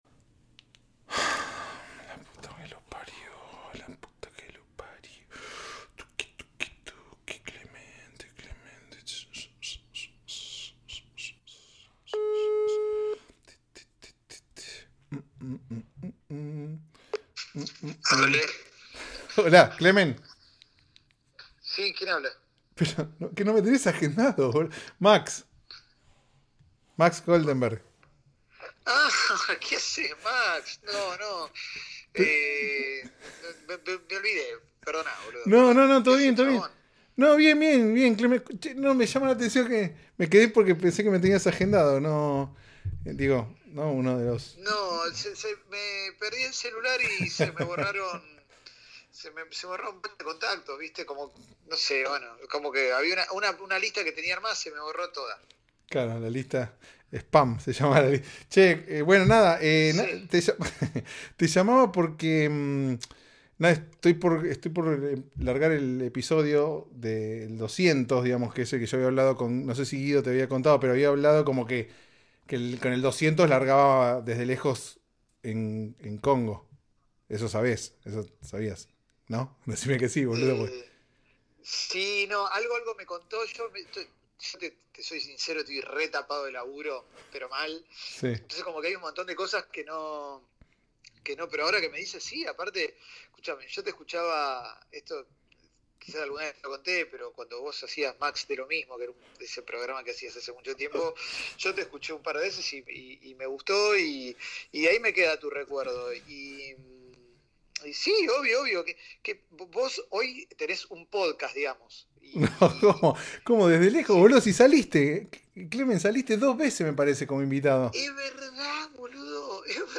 Lo llamé a Manu Ginóbili a ver en qué anda su vida de jubilado.